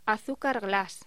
Locución: Azúcar glas